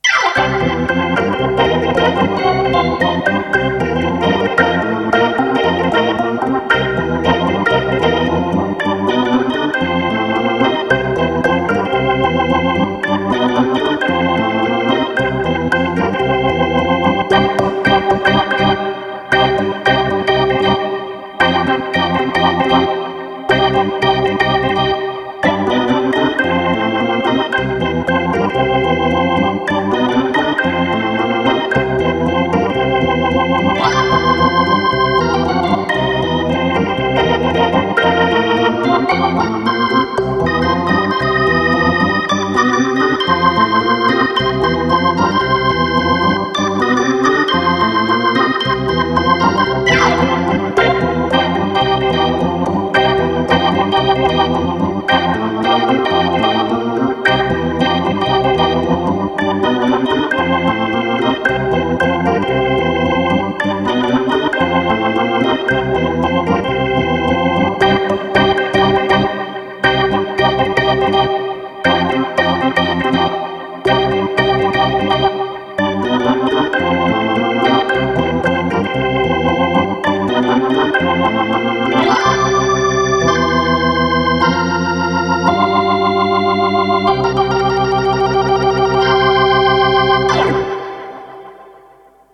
Viscount organ.